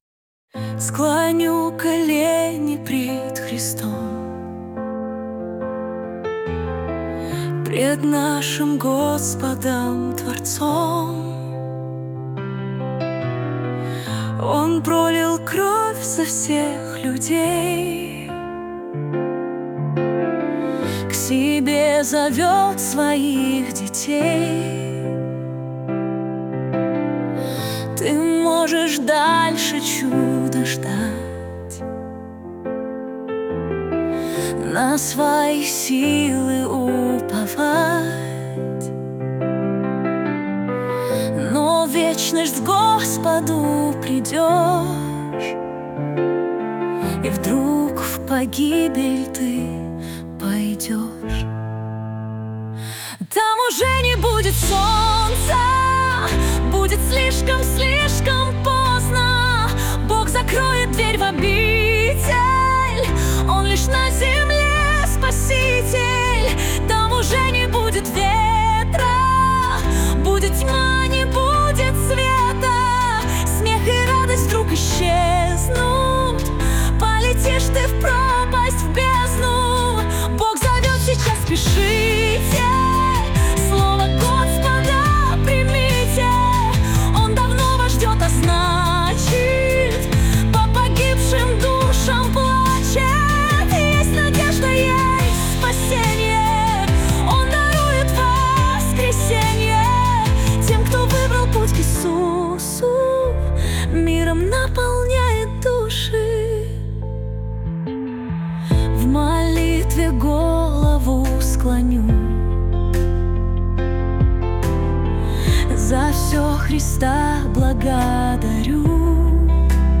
песня ai
357 просмотров 1229 прослушиваний 95 скачиваний BPM: 71